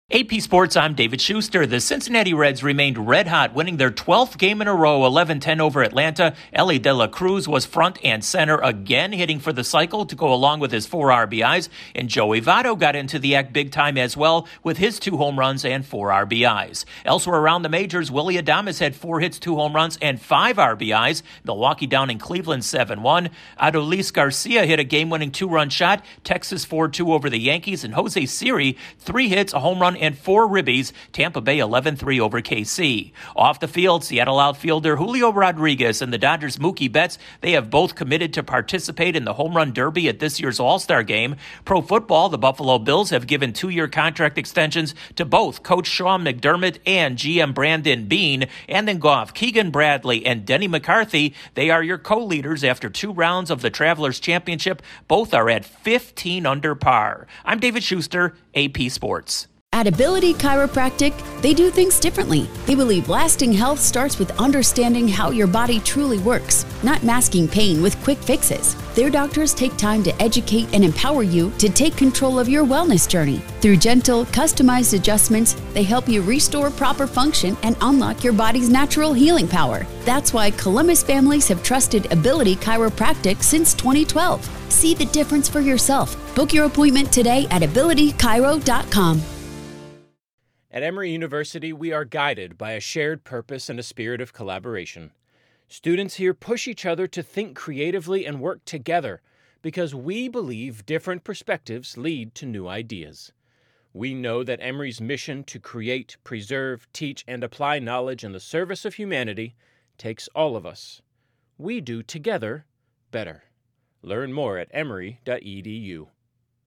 The Reds keep rolling and the Brewers keep pace, the Rangers outlast the Yankees, two join the Home Run Derby list, the Bills reward two important cogs and the scores are low at the Travelers Championship. Correspondent